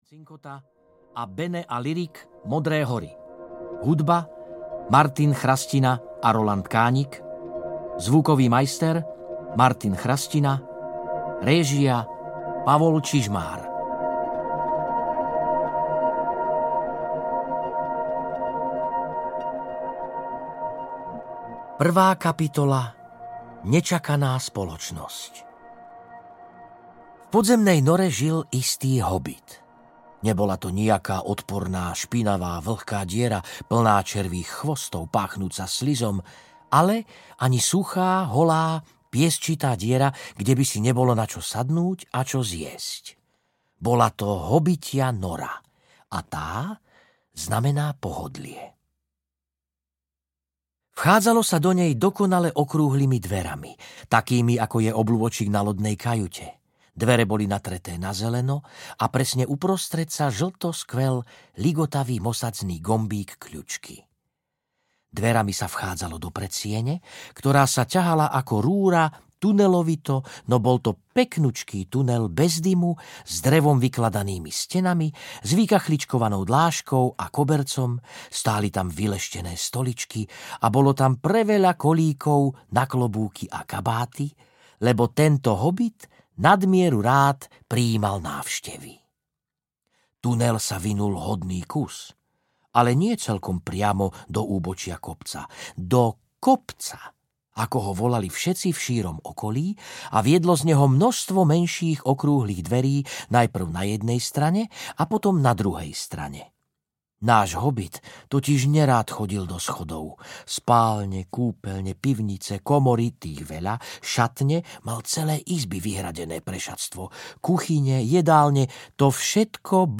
Hobit audiokniha
Ukázka z knihy